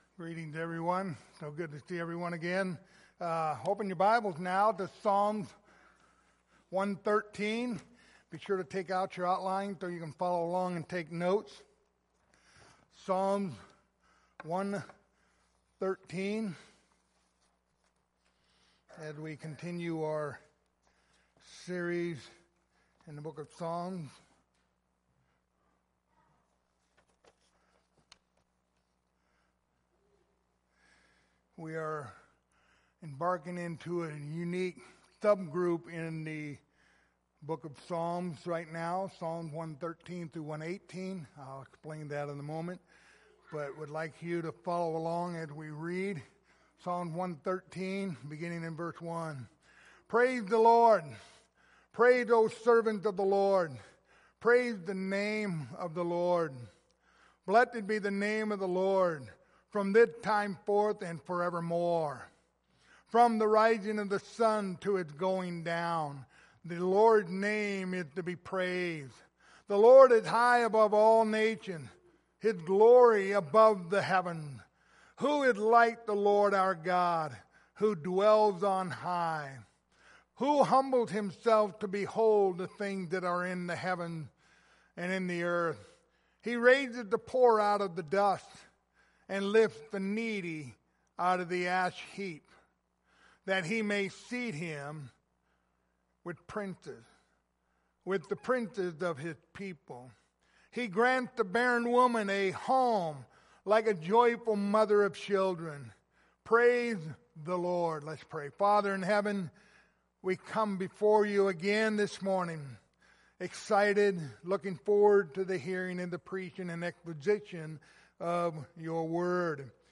The book of Psalms Passage: Psalms 113 Service Type: Sunday Morning Topics